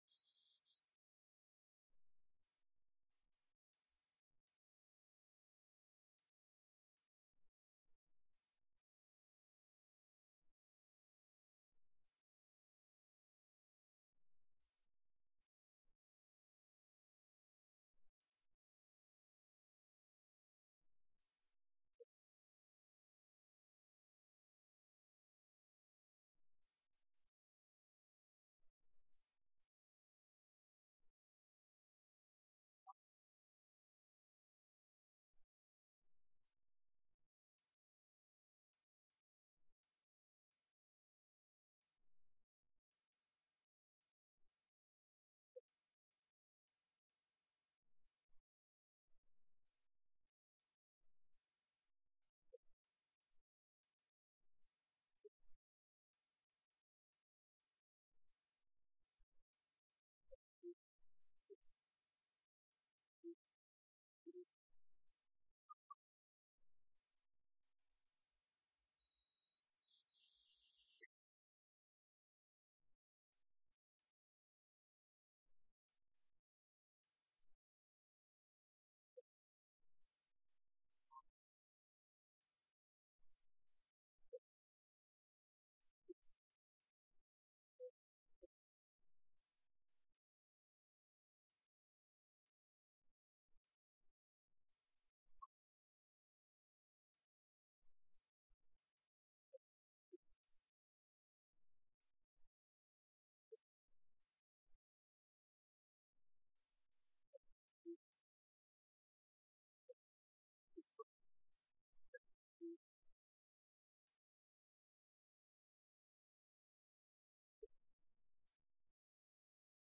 تاريخ النشر ١١ ذو الحجة ١٤٣٦ هـ المكان: المسجد النبوي الشيخ: فضيلة الشيخ د. علي بن عبدالرحمن الحذيفي فضيلة الشيخ د. علي بن عبدالرحمن الحذيفي من فضائل الأذكار